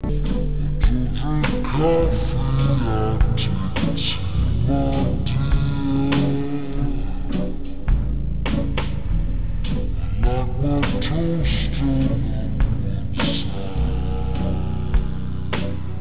The signal contained in music.au was sampled at 16 kHz, so it will sound much too slow when played backat the default 8 kHz sampling rate.
music.au